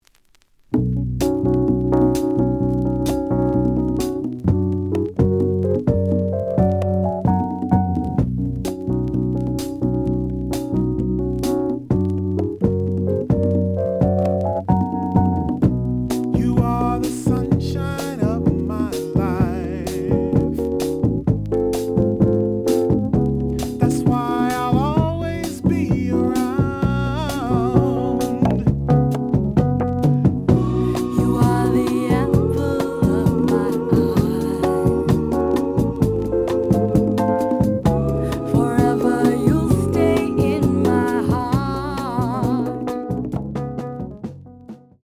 The audio sample is recorded from the actual item.
●Genre: Soul, 70's Soul
Slight noise on beginning of A side, but almost good.)